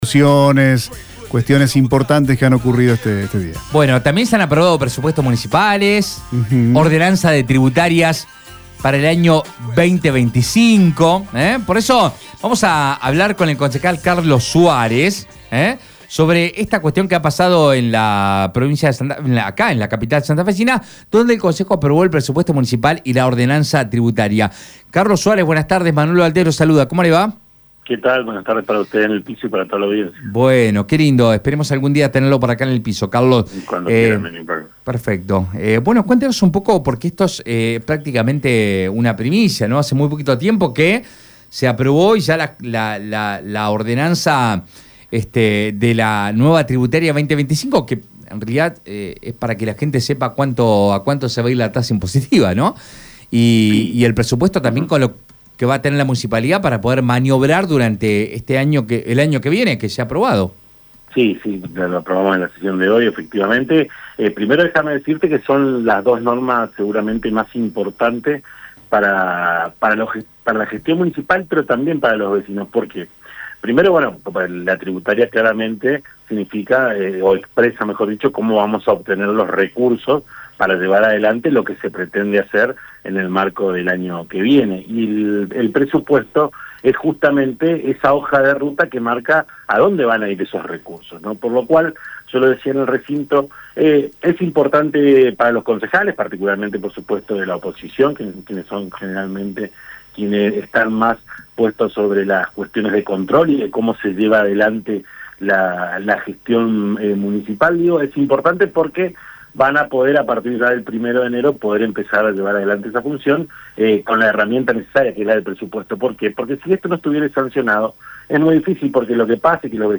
Escucha la palabra del concejal Carlos Suarez en Radio EME: